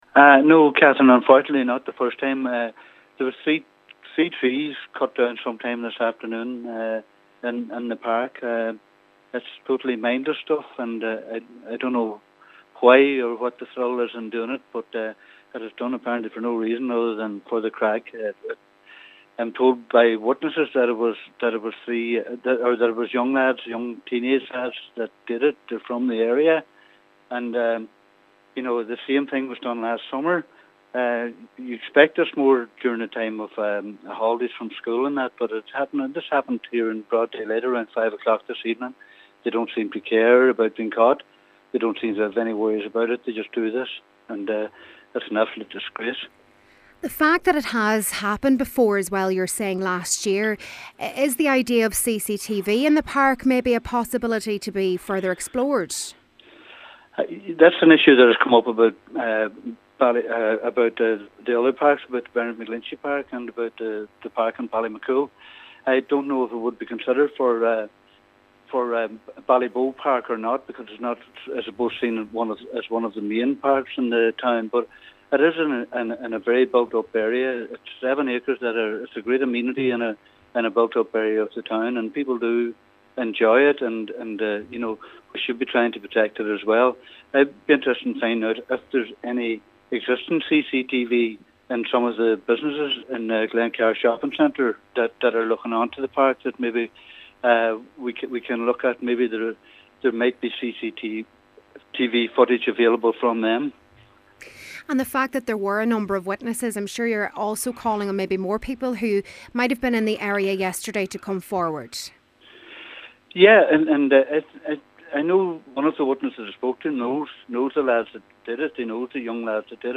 Mayor Cllr. Jimmy Kavanagh has condemned the incident and says while a number of people witnessed the attack, the vandals may have been caught on CCTV from local businesses in the area: